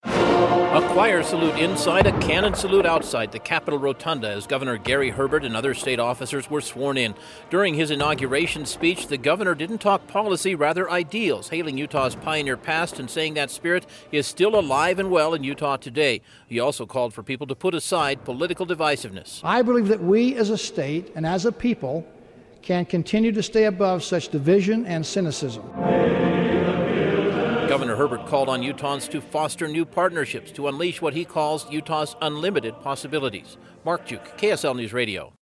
Utah's Governor took a new oath of office this morning, along with other state officers, in a ceremony that involved song, ceremony and salutes.